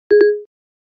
Звуки Siri
Звук, когда Сири не расслышала вопрос или отключилась